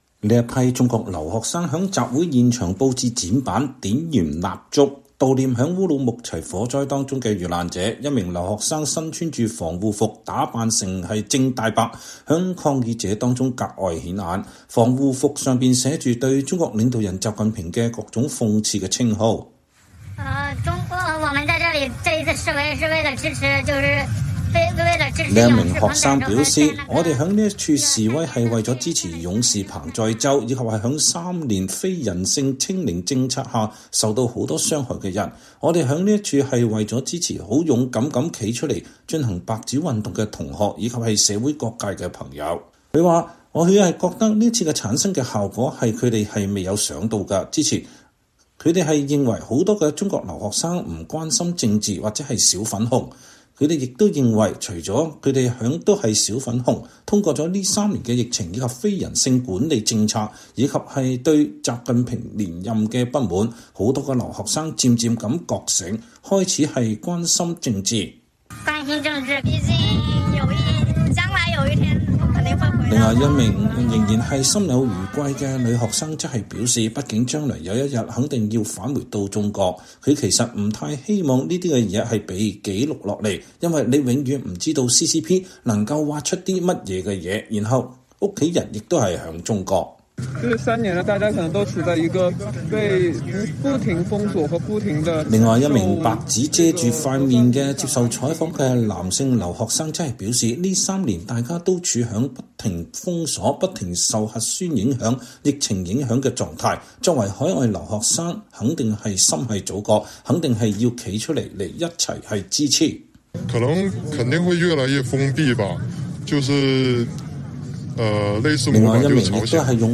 12月4日，一批在意大利的中國留學生在羅馬地標建築鬥獸場外舉行集會，抗議習近平的清零政策，聲援連續多日在中國各地發生的抗議活動。這批留學生手持白紙，表達對“白紙革命”的支持。